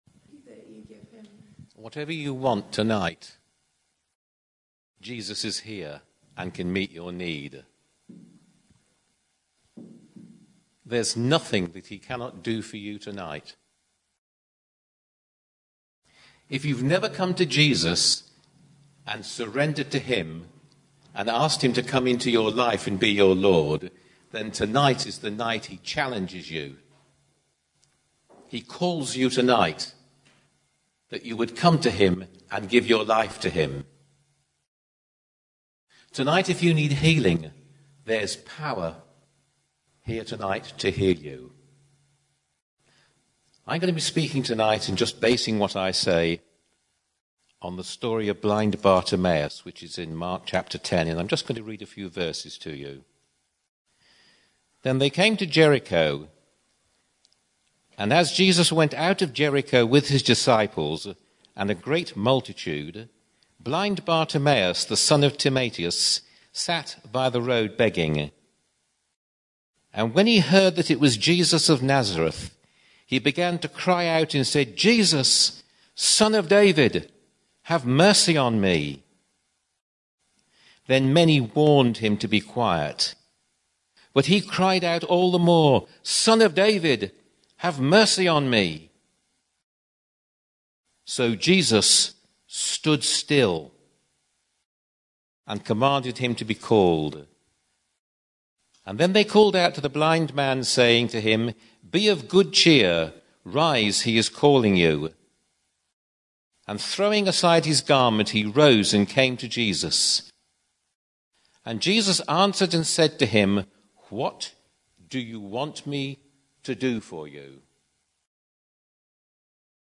Healing Service